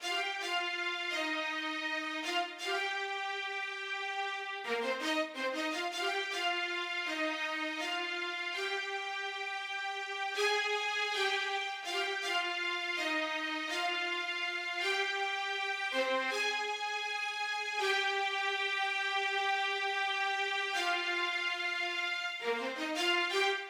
11 strings 2 C.wav